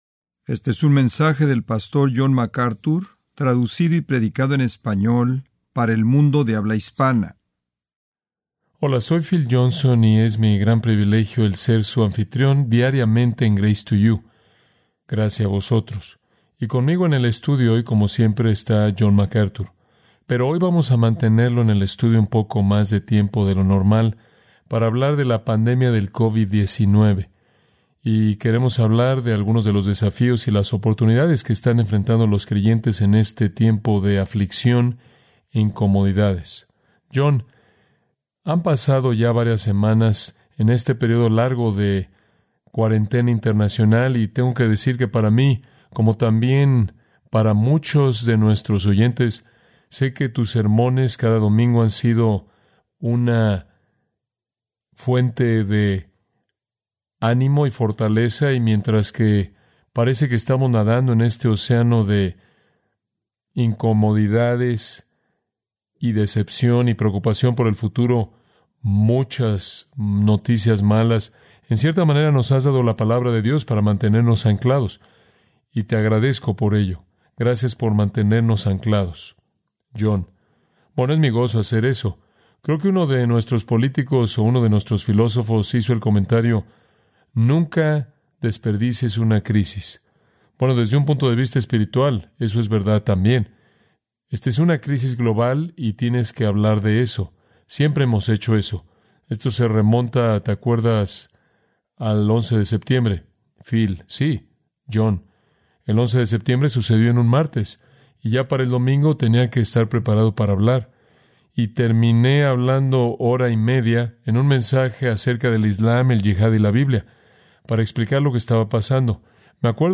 Pensando conforme a la Biblia acerca de la Pandemia del COVID-19: Una entrevista con John MacArthur